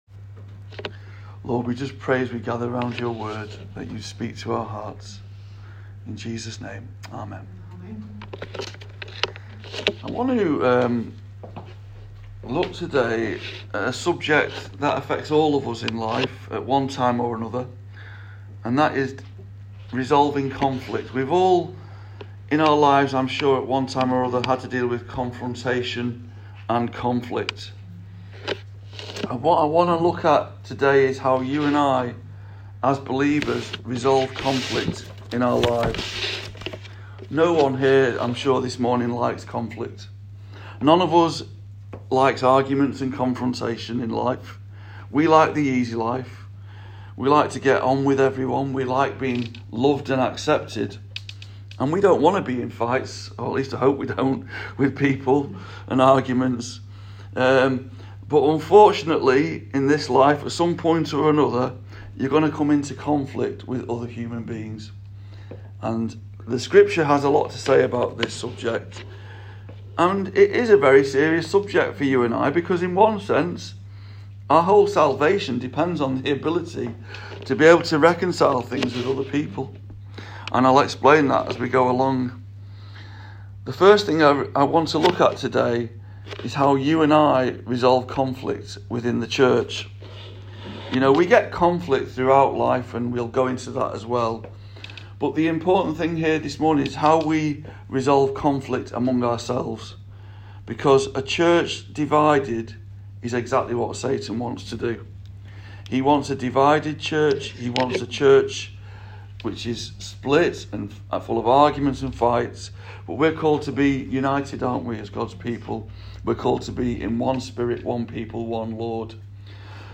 SERMON “RESOLVE CONFLICT”